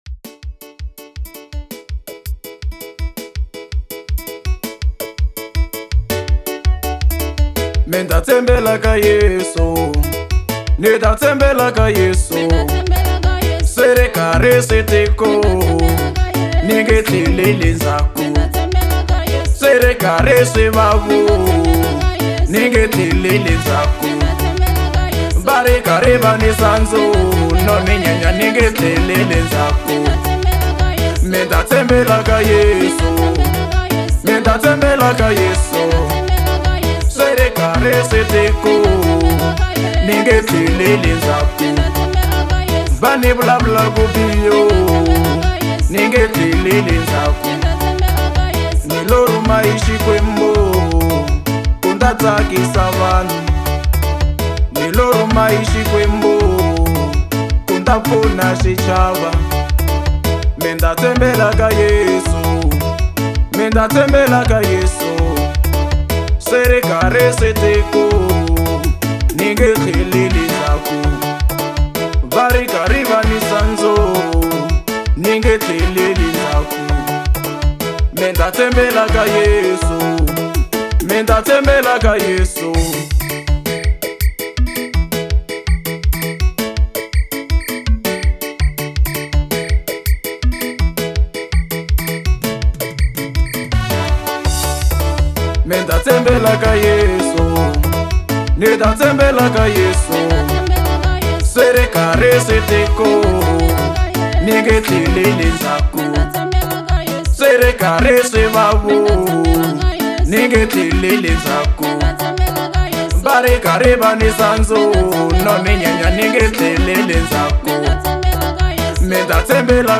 Genre : Gospel